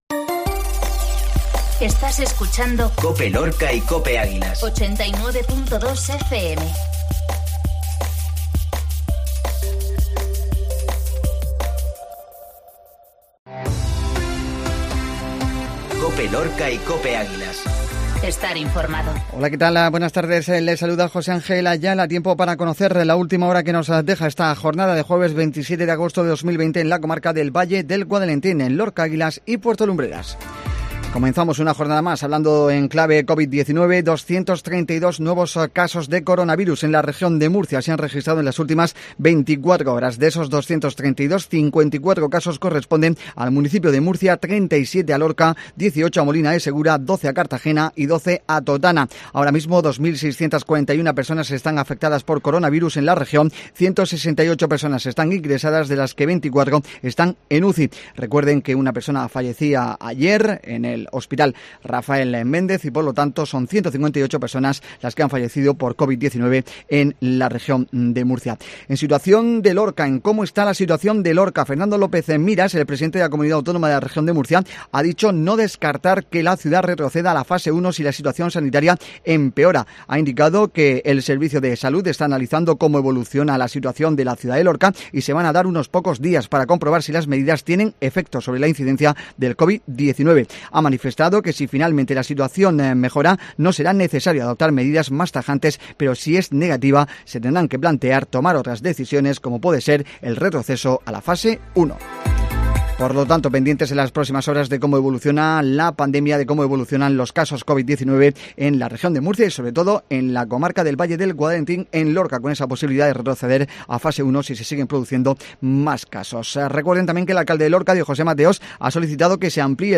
INFORMATIVO MEDIODÍA COPE JUEVES